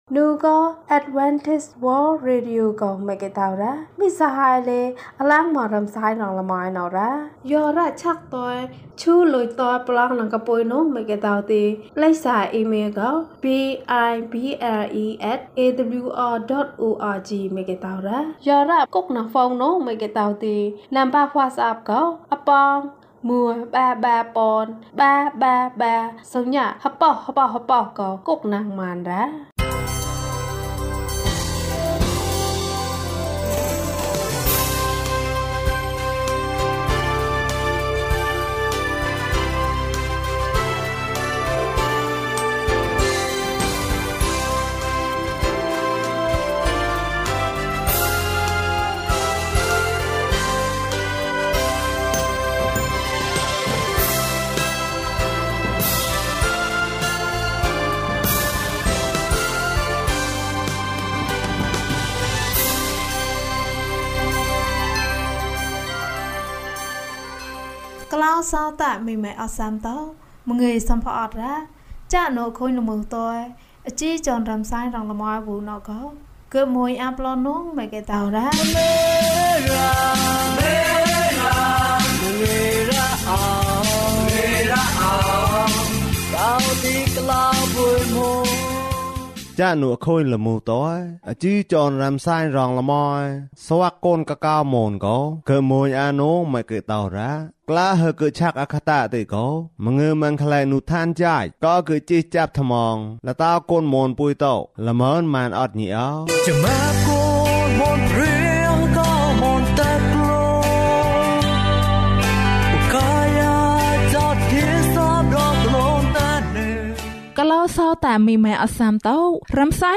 ခရစ်တော်ထံသို့ ခြေလှမ်း ၅၂ ကျန်းမာခြင်းအကြောင်းအရာ။ ဓမ္မသီချင်း။ တရားဒေသနာ။